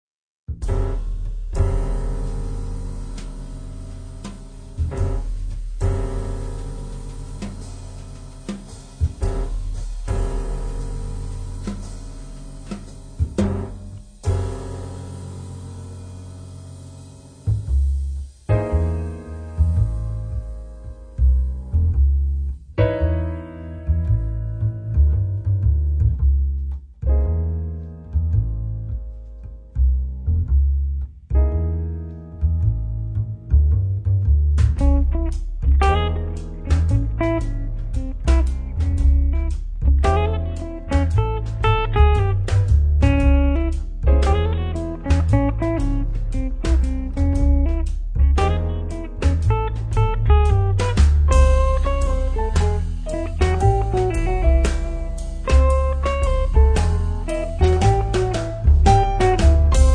chitarra
pianoforte
contrabbasso
batteria
Il suono del gruppo risulta compatto